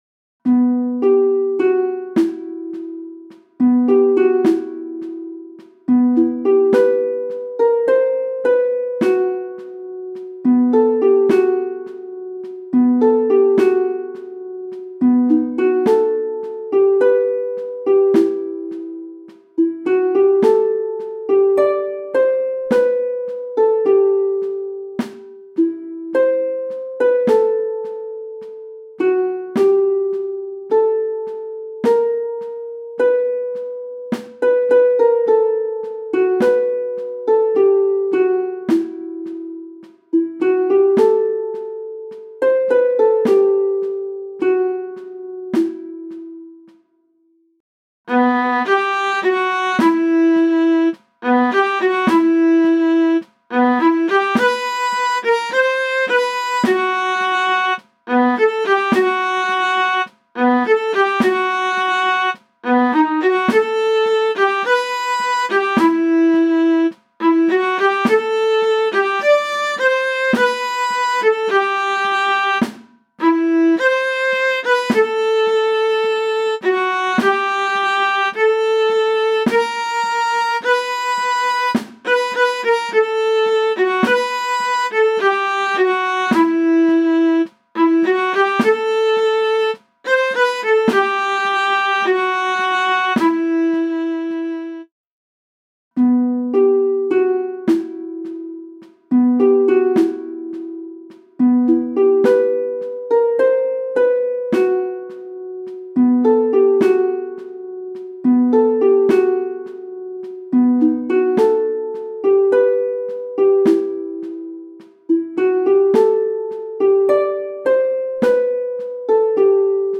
MIDI - 1-stimmig
midi_im-feuerkreis_1-stimmig_320.mp3